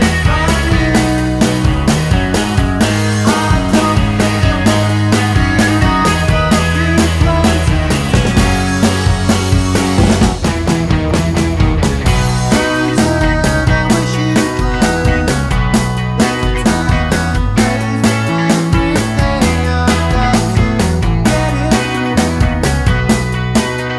no Backing Vocals Indie / Alternative 3:46 Buy £1.50